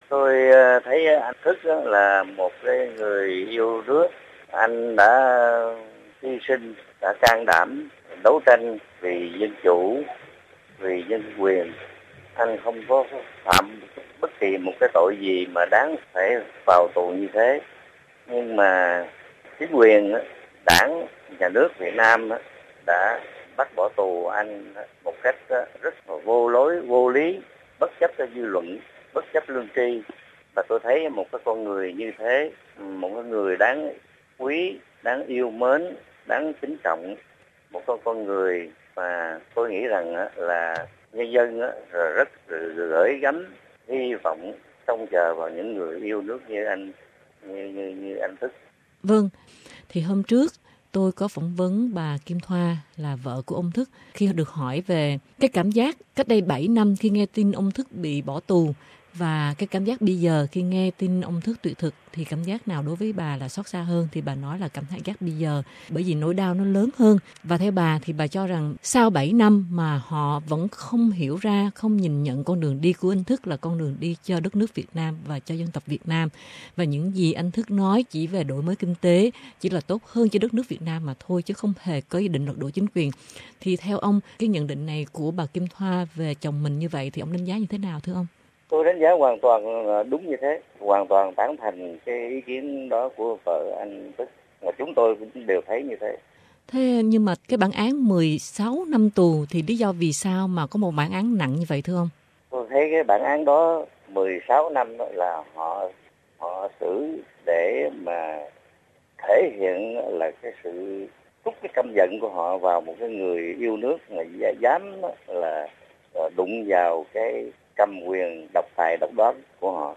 hỏi chuyện